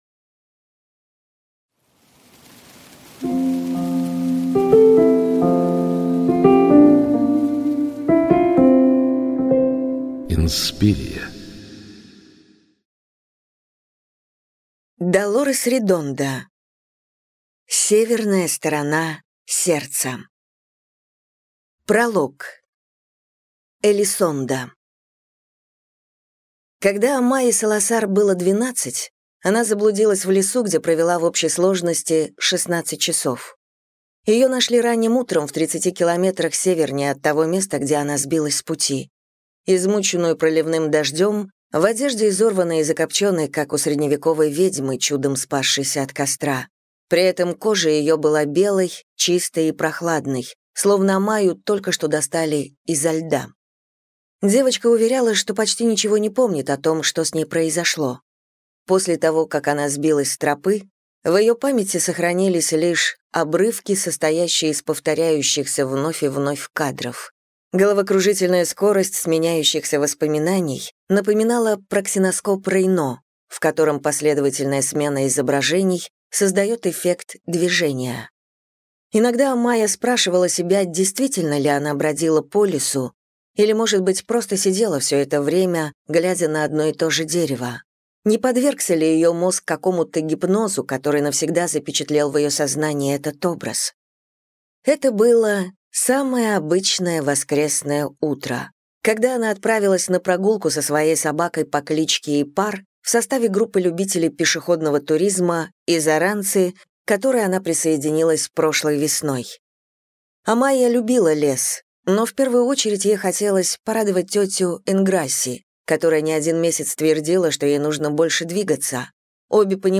Аудиокнига Северная сторона сердца | Библиотека аудиокниг